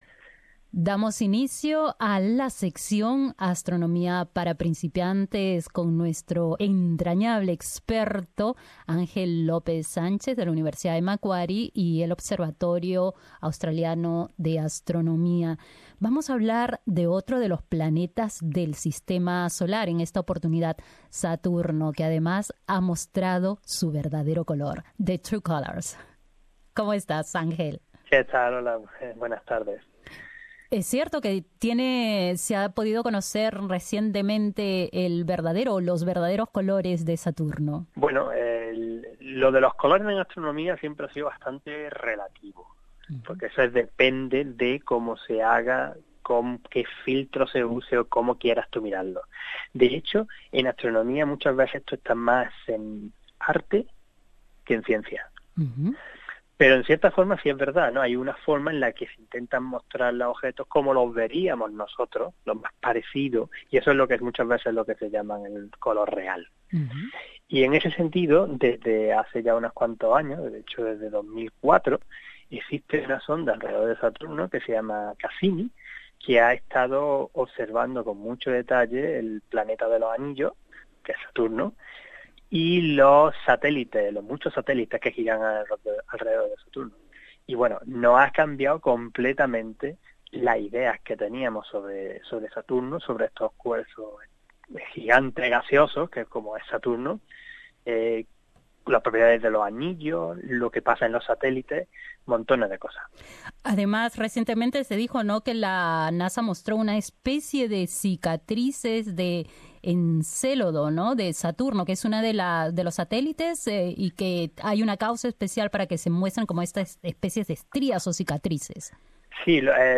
En Astronomía para principiantes dialogamos con nuestro experto